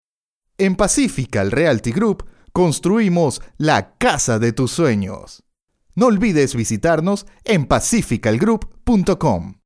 spanisch Südamerika
Sprechprobe: Sonstiges (Muttersprache):